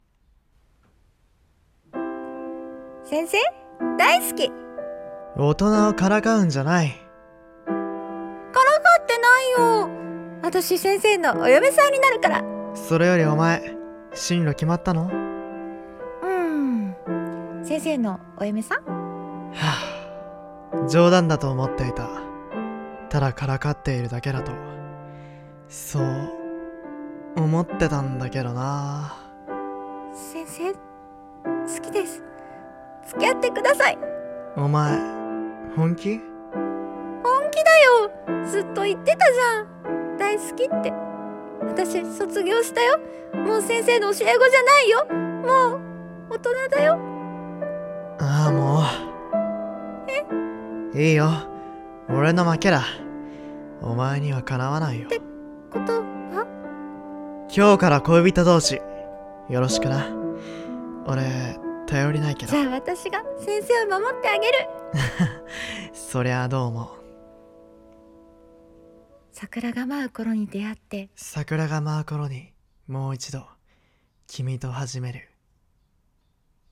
桜が舞う頃に、君と。【2人声劇】